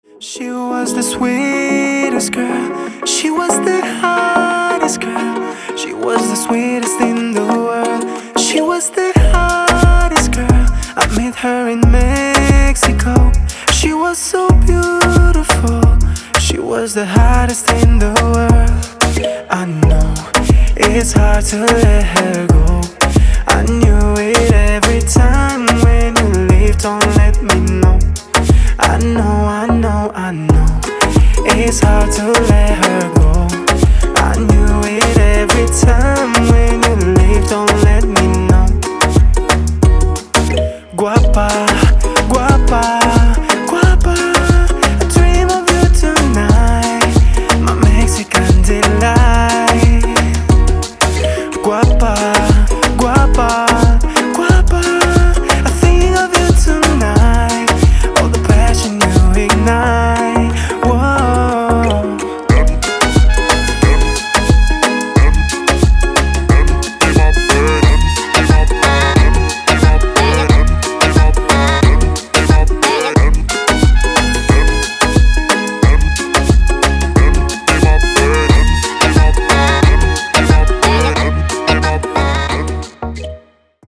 • Качество: 192, Stereo
поп
гитара
мужской вокал
dance
спокойные
романтичные
труба